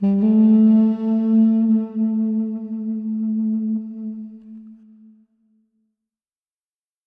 Звуки дудука
Устойчивый звук